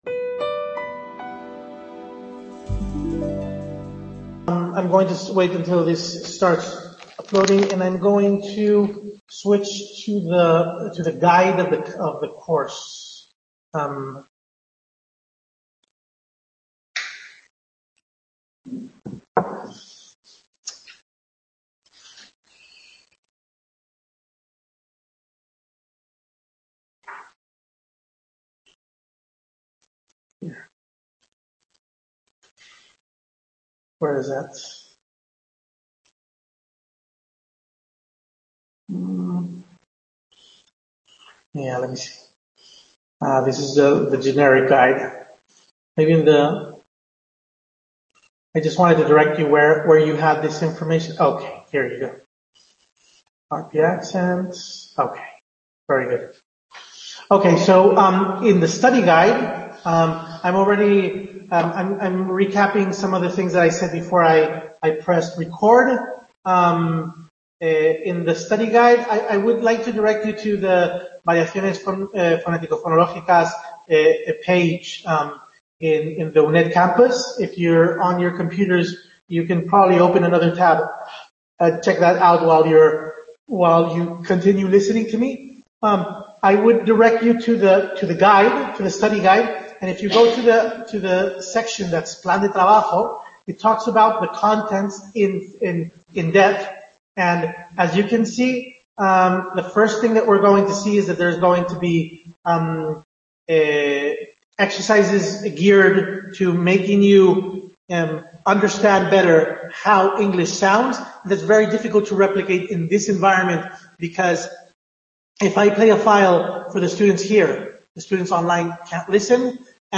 Webconference celebrated at the Madrid Sur Center on February 20th